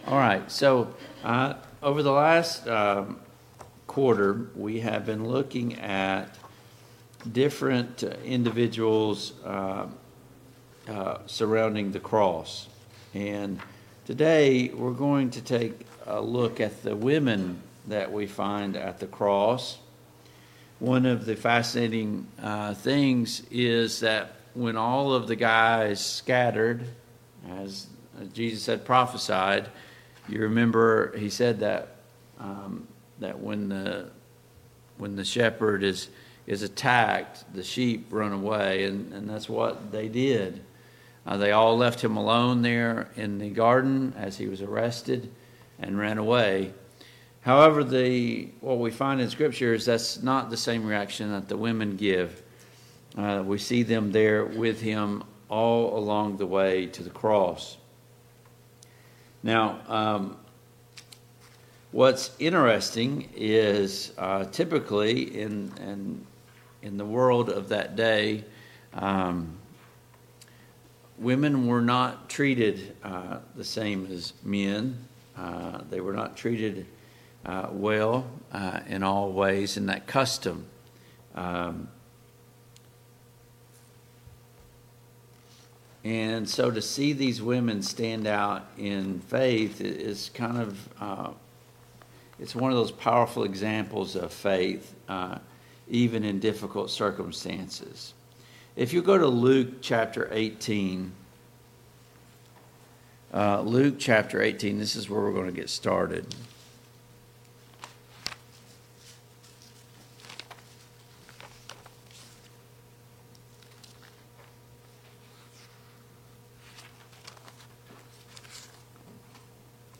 The Cast of the Cross Service Type: Sunday Morning Bible Class Topics: The Women of the Cast of the Cross « 8.